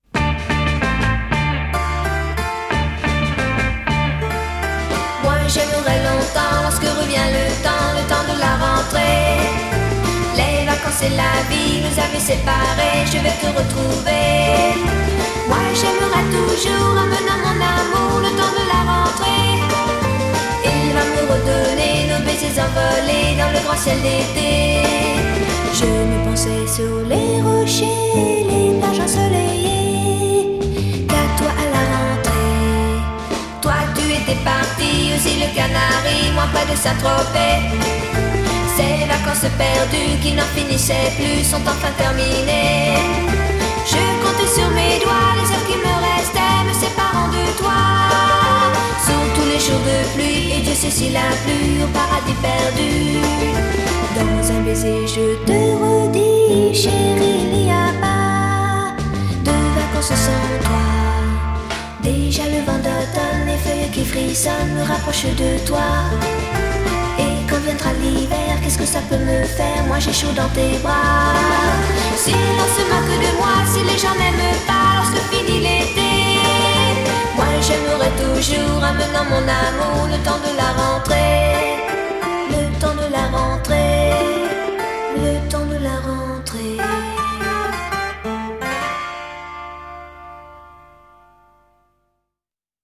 Made French Pop undeniably sexy.